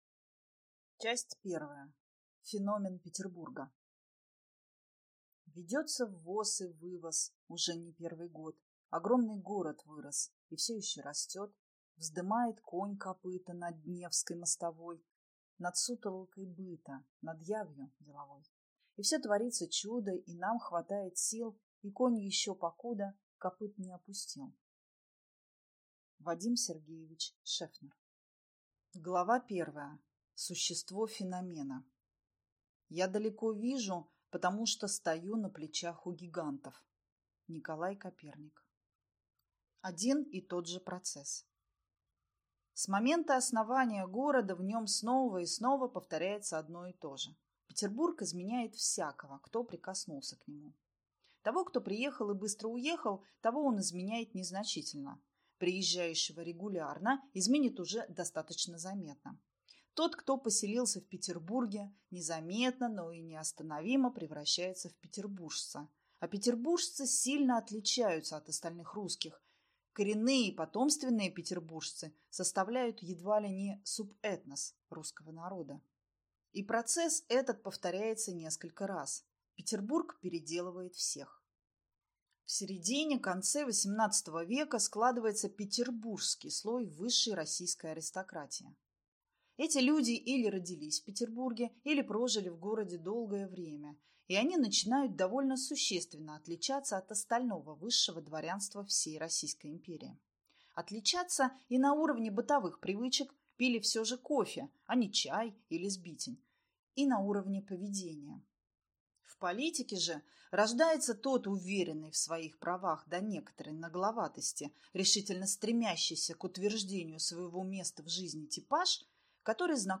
Аудиокнига Столица на костях. Величие и проклятие Петербурга | Библиотека аудиокниг